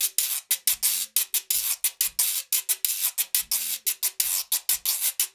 90 GUIRO 2.wav